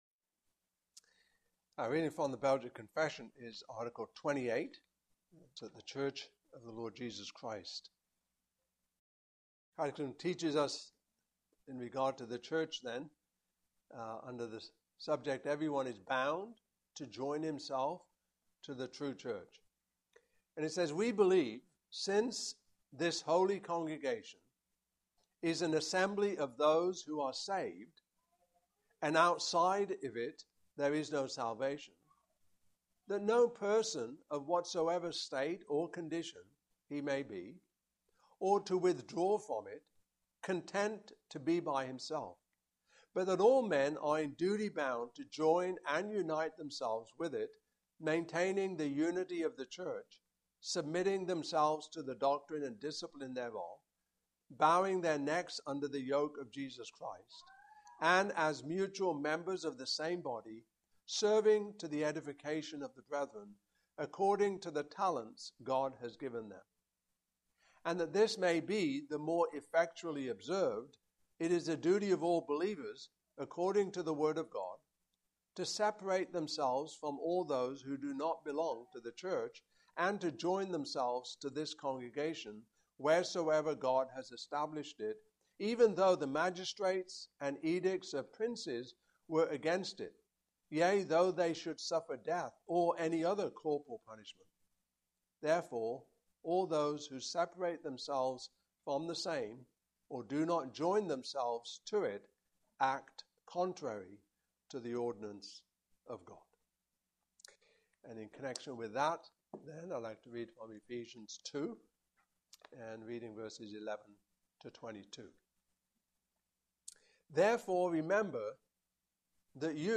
Passage: Ephesians 2:11-22 Service Type: Evening Service